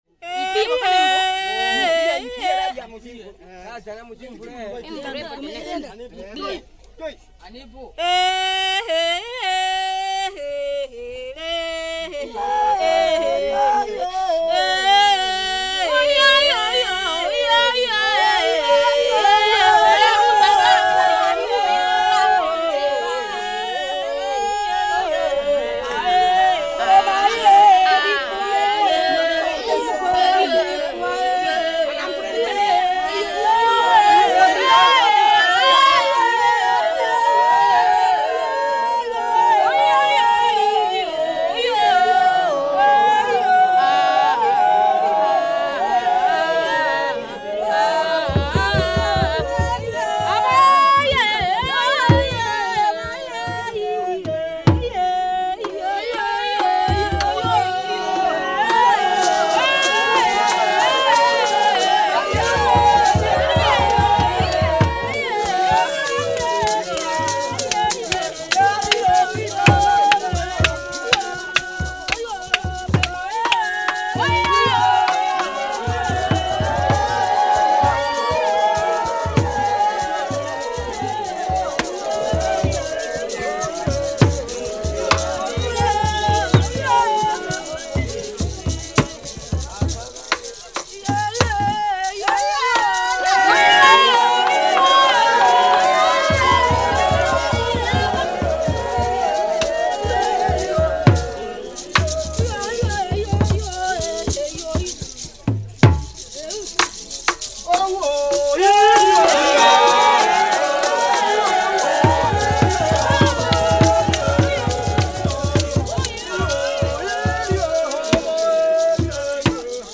Pygmy dance I TR125-11.mp3 of Pygmy dance I